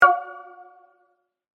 Apple Pay Failed Sound Effect Download: Instant Soundboard Button